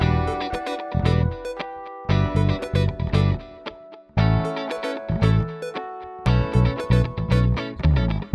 01 Backing PT1.wav